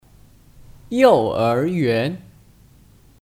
幼儿园 Yòu’éryuán (Kata benda): Taman kanak-kanak